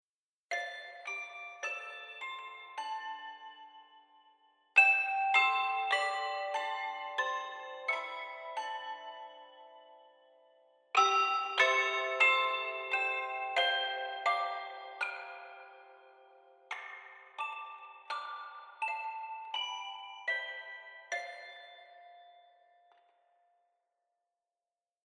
Spiezeugklaviere von Albert Schönhut waren schon Kandidaten in Teil 2 des Tests.